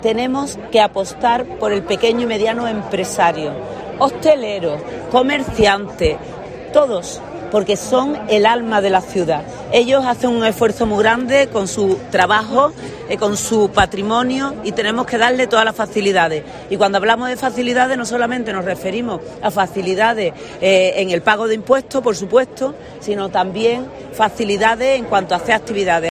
Pilar Miranda, alcaldesa de Huelva